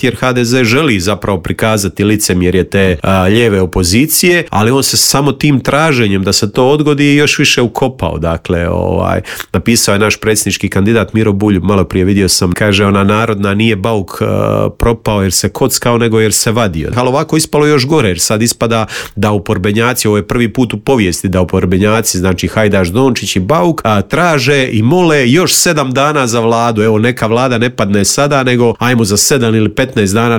Svoj sud u Intervjuu Media servisa rekao nam je saborski zastupnik iz MOST-a Nikola Grmoja: